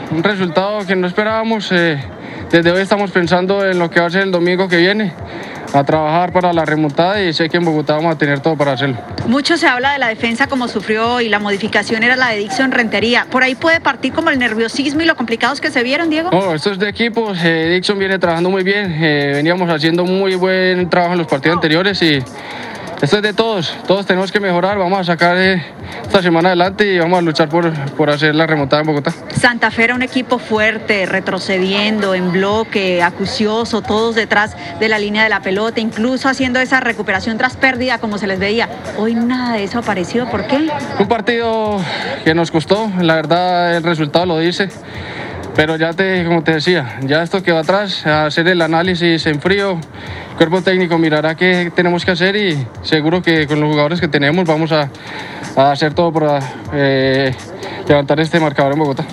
en entrevista con Win Sports